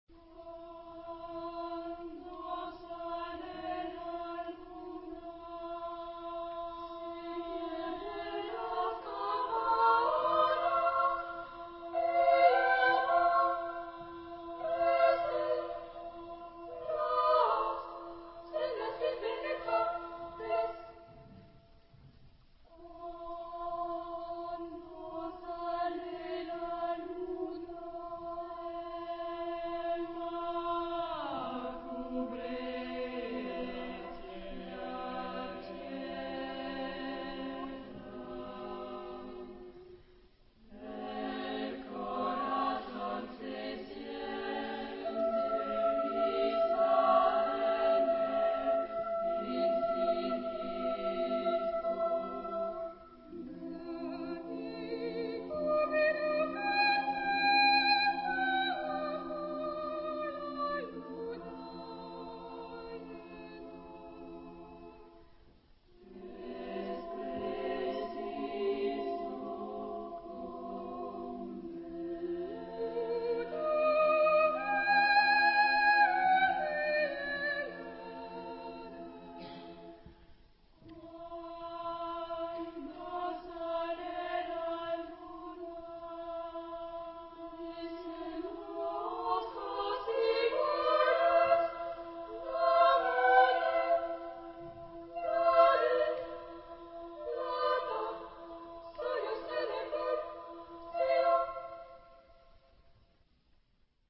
Genre-Style-Form: Choral song ; Secular ; Poetical song
Type of Choir: SSAA  (4 children OR women voices )
Soloist(s): Soprano (1)  (1 soloist(s))
Tonality: E tonal center